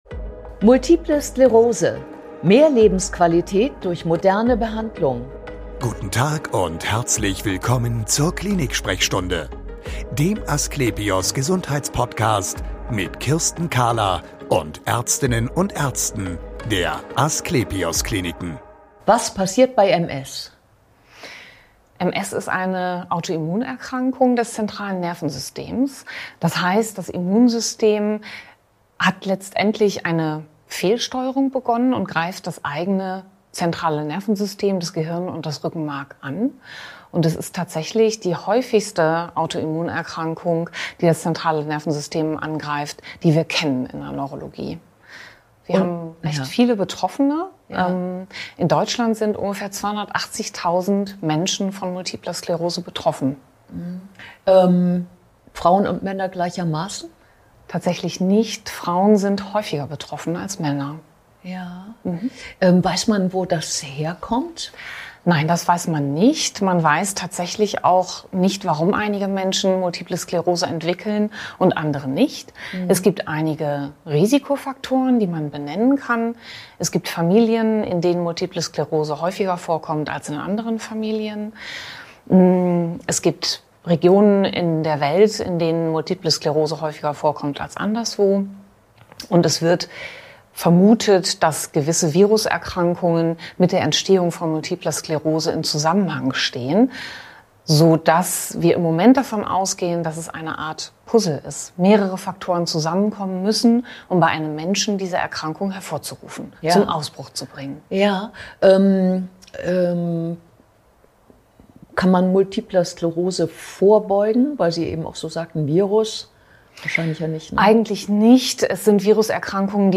Sie spricht ausführlich über den Verlauf der Erkrankung und die Bedeutung von Schüben.